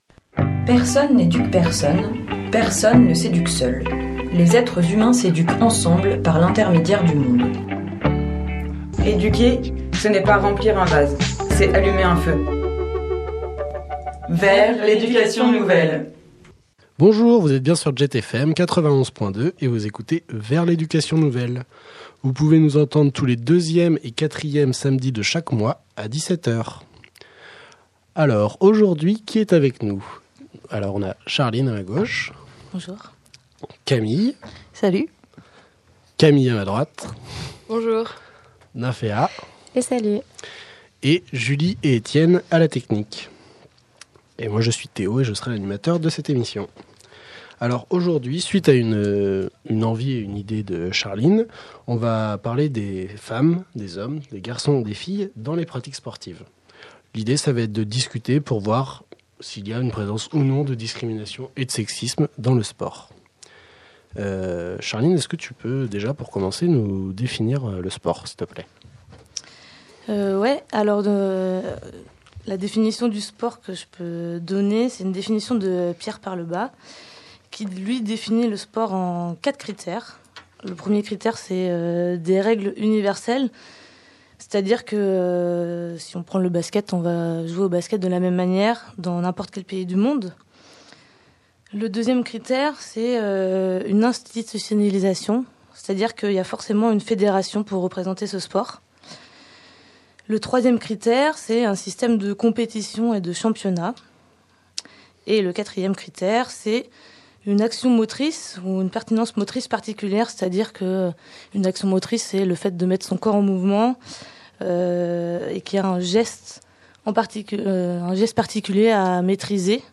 Pour cela, 4 invitées viennent nous parler de leurs pratiques sportives et de la difficulté d’être une femme dans un milieu majoritairement masculin.